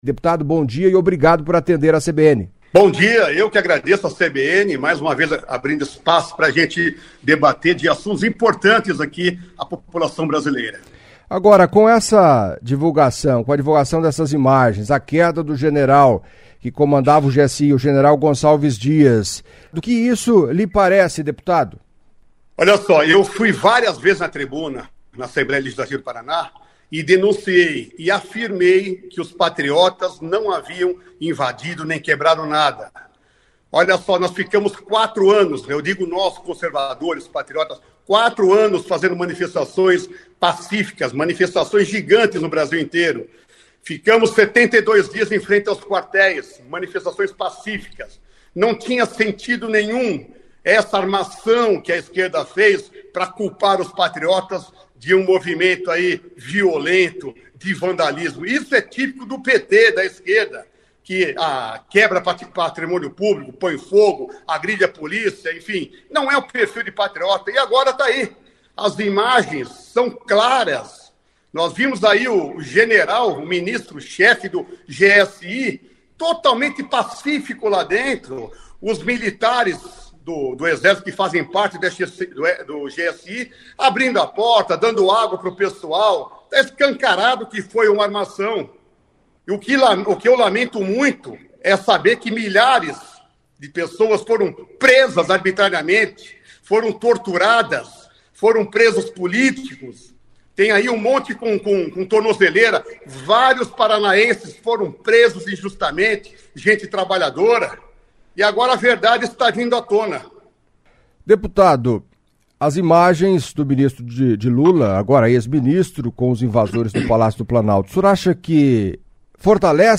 O deputado estadual Ricardo Arruda, do PL, foi o primeiro a participar.